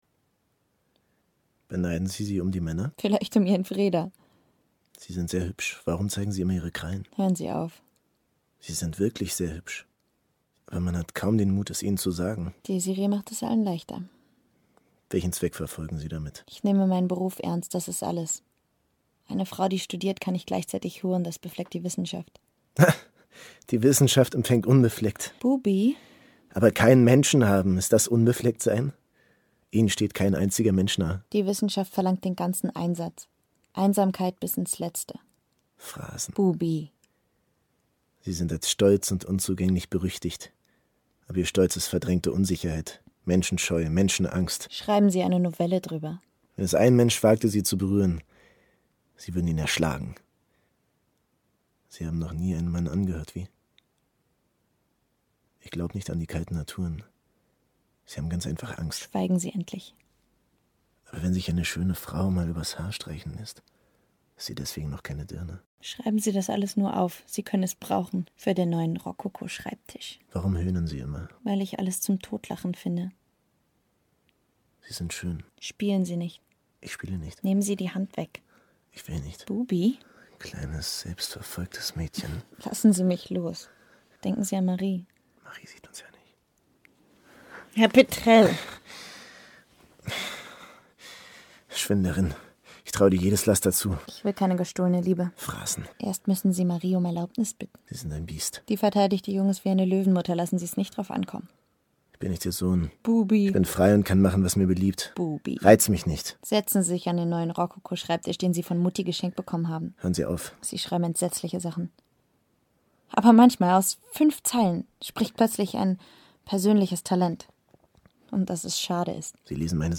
Sprecher | Die internationale Sprecheragentur World Wide Voices
Sprechprobe: Industrie (Muttersprache):